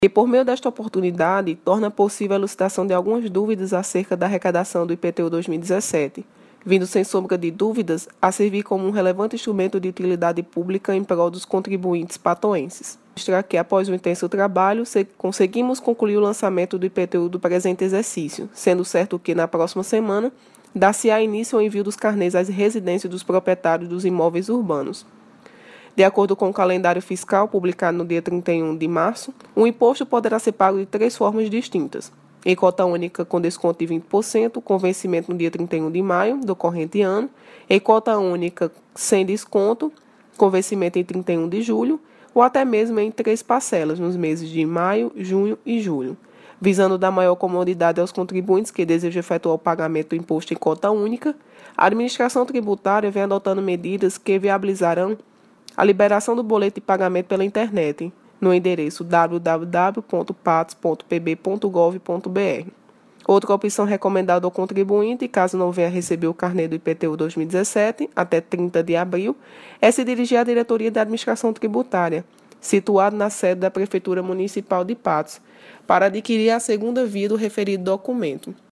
Fala